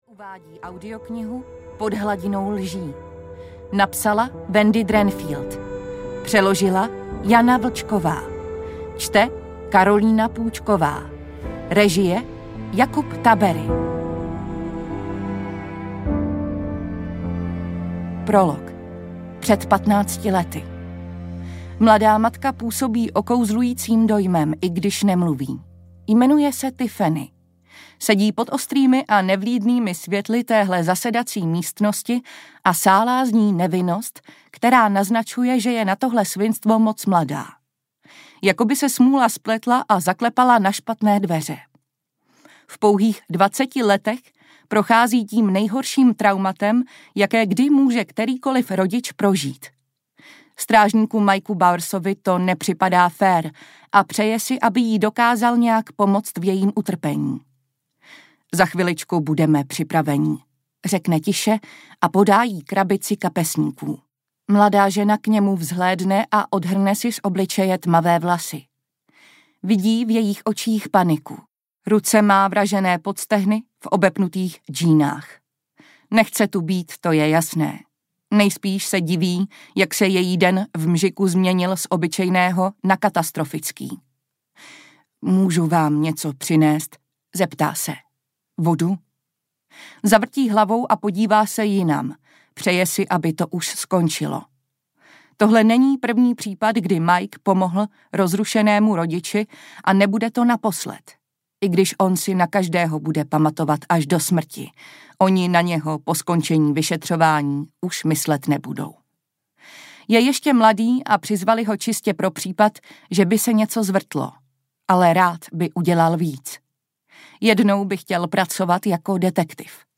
Pod hladinou lží audiokniha
Ukázka z knihy